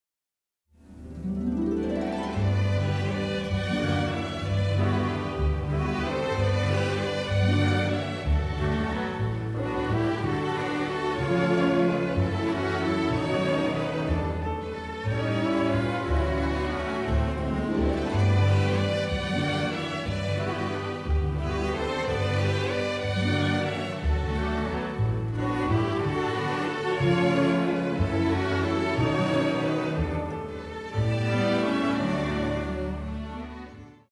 jazz-rooted score